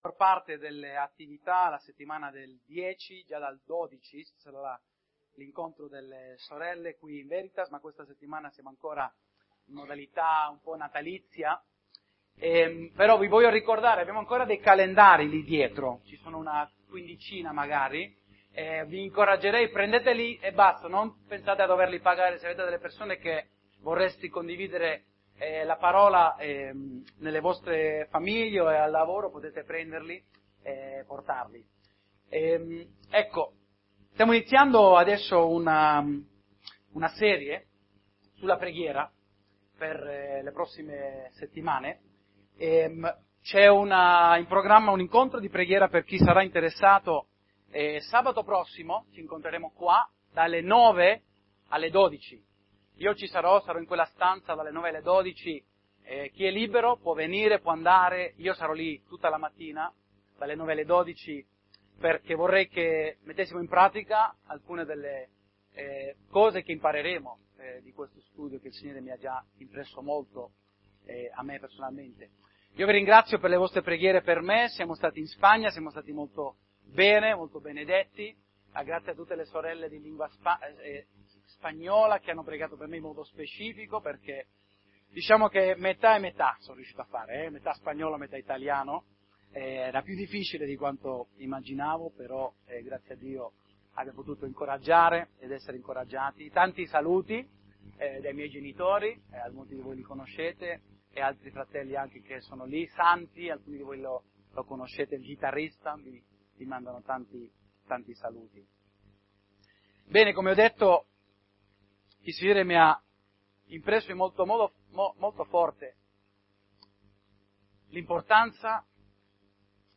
Tutti i sermoni Preghiera Parte 1 17 Gennaio